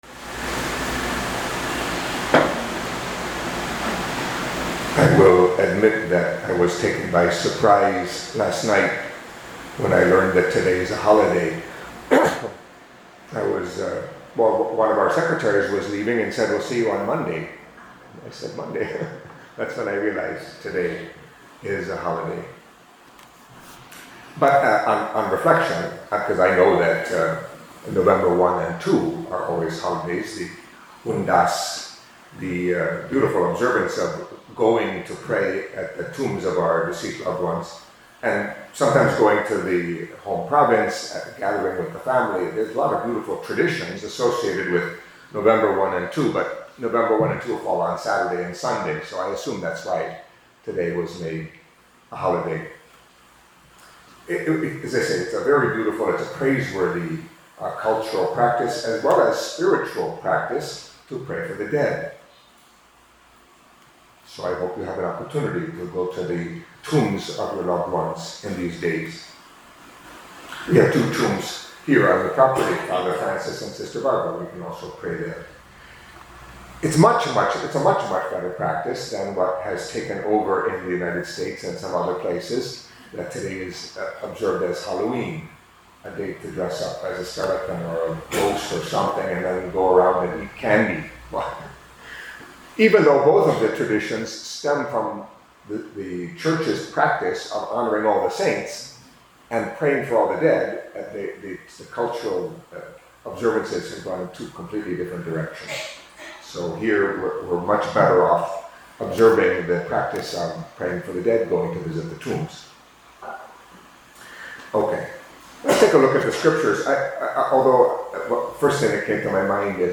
Catholic Mass homily for Friday of the Thirtieth Week in Ordinary Time